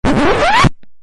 CD riscado disco fita
cd-riscado-disco-fita.mp3